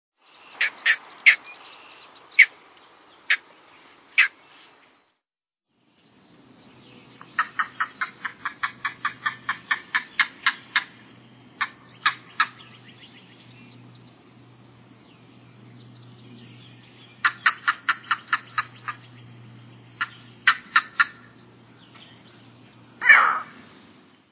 Green Heron
It can be difficult to see as it stands motionless waiting for small fish to approach within striking range, but it frequently announces its presence by its loud squawking.
Bird Sound
Flight or alarm call an explosive "skeow." Also make series of "kuk-kuk-kuk-kuk" notes.
GreenHeron.mp3